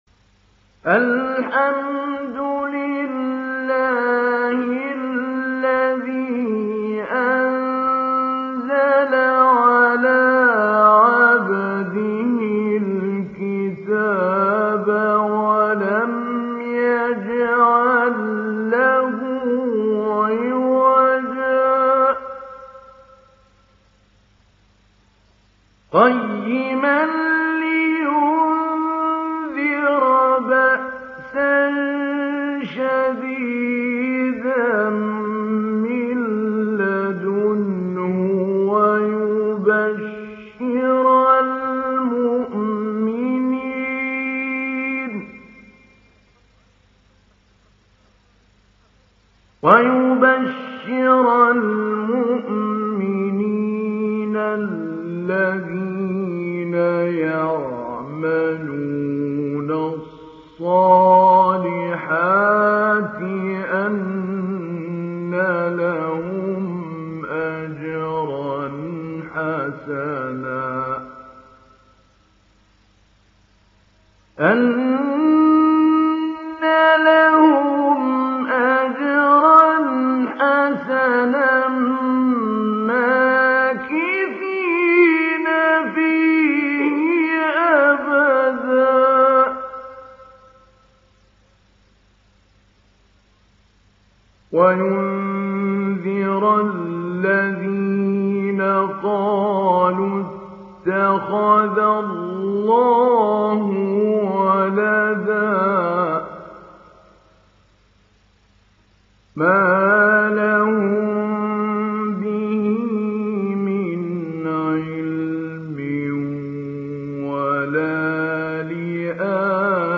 ডাউনলোড সূরা আল-কাহফ Mahmoud Ali Albanna Mujawwad